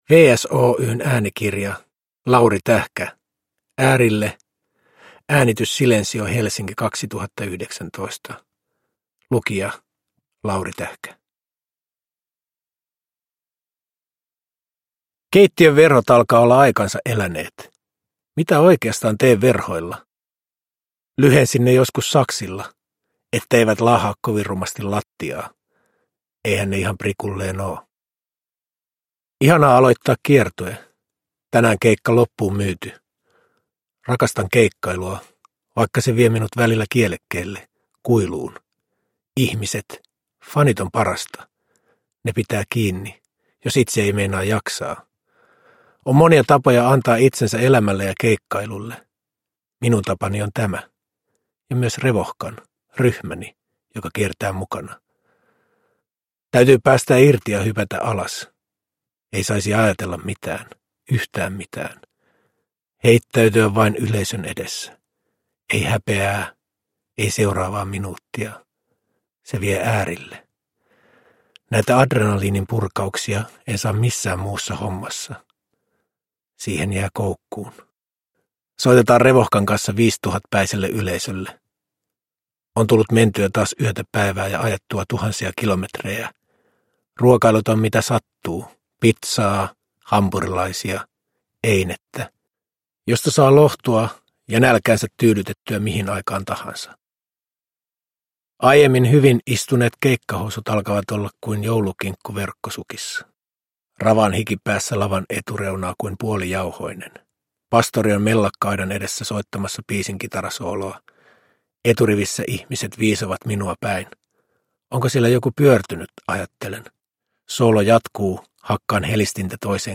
Äärille – Ljudbok – Laddas ner
Uppläsare: Lauri Tähkä